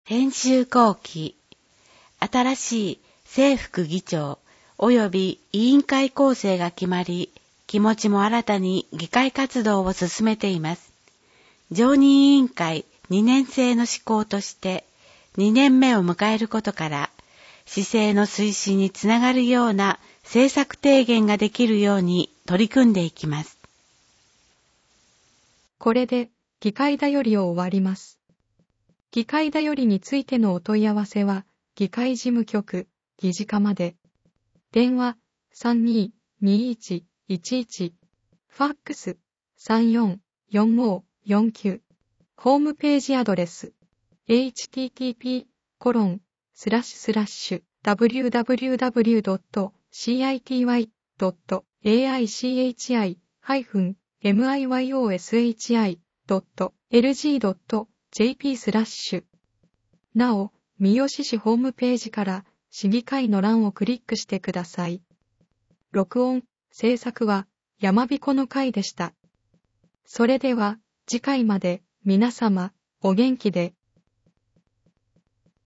『声の議会広報』は、「みよし議会だより きずな」を音声情報にしたもので、平成29年6月15日発行の第110号からボランティア団体「やまびのこ会」の協力によりサービス提供をはじめました。（一部AI自動音声（テキスト読み上げ）ソフト「VOICEVOX Nemo」を使用）